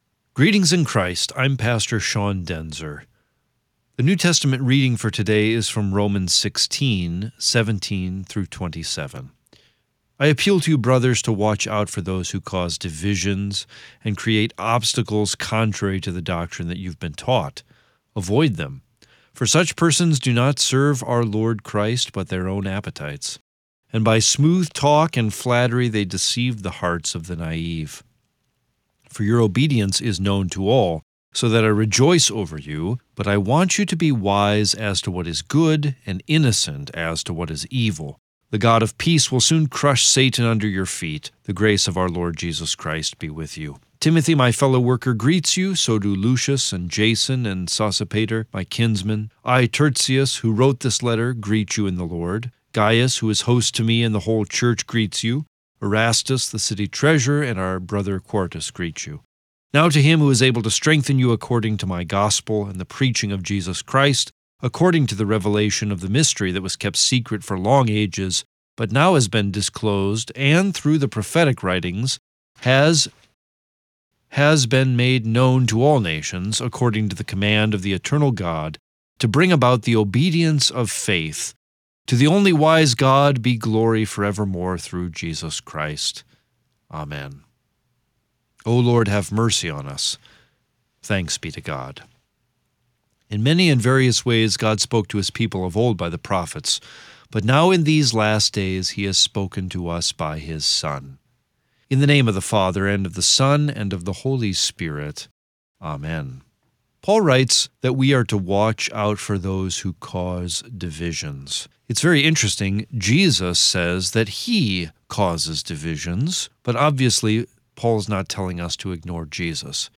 Morning Prayer Sermonette: Romans 16:17-27
Hear a guest pastor give a short sermonette based on the day’s Daily Lectionary New Testament text during Morning and Evening Prayer.